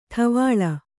♪ ṭha